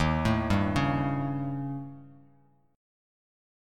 Eb7b5 chord